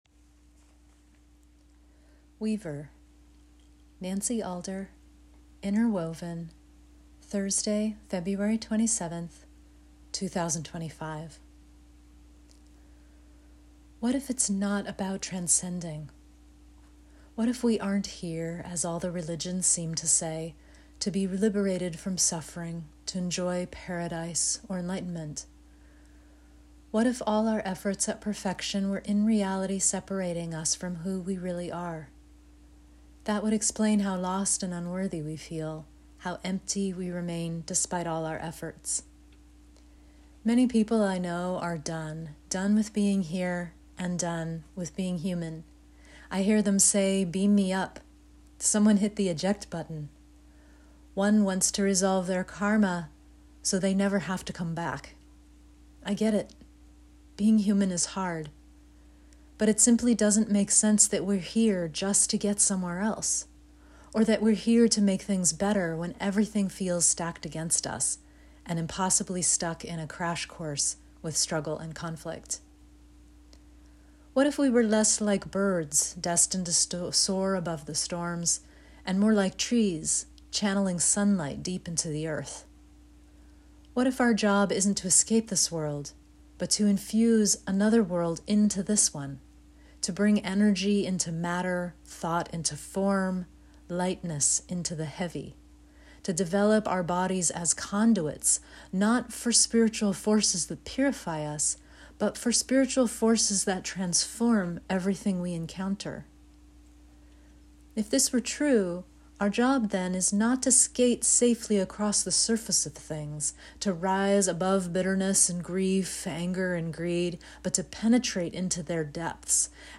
Enjoy this 8-minute read, or let me read it to you via the recording at the top of the webpage!